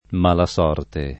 vai all'elenco alfabetico delle voci ingrandisci il carattere 100% rimpicciolisci il carattere stampa invia tramite posta elettronica codividi su Facebook mala sorte [ m # la S0 rte ] (meno com. malasorte [id.]) s. f.